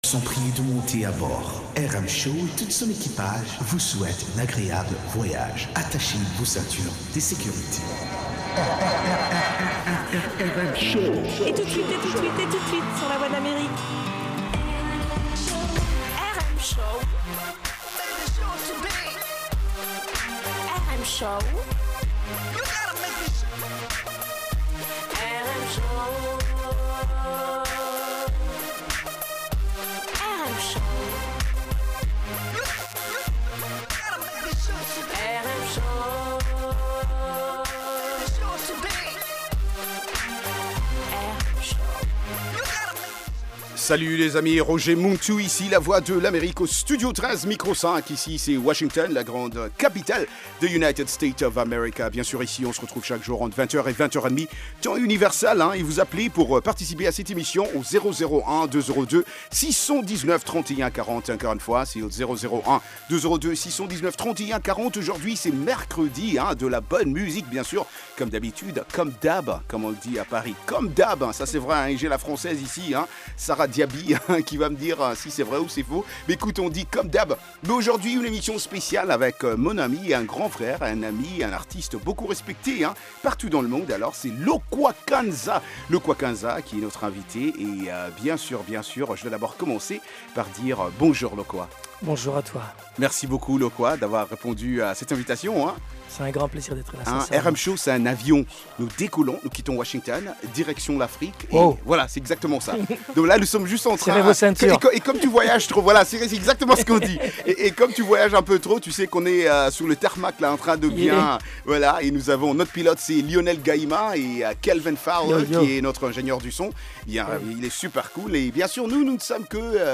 Musique internationale & comedie